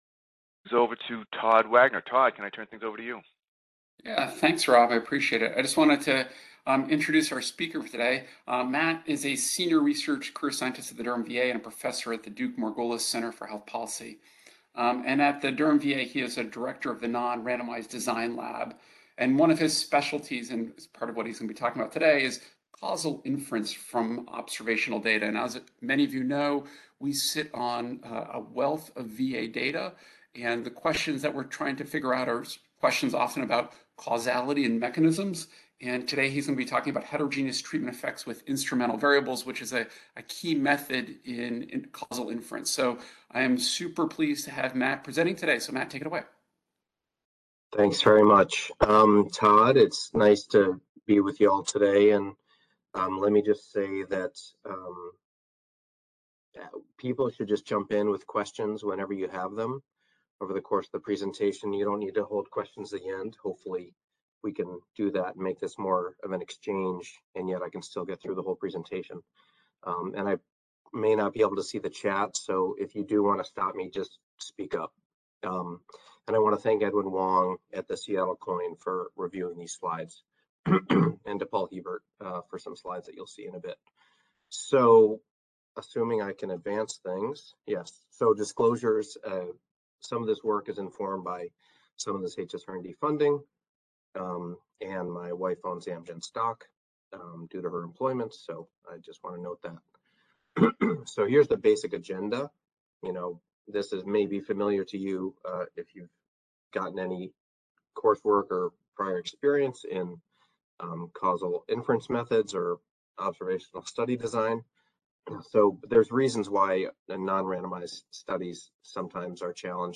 HERC Health Economics Seminar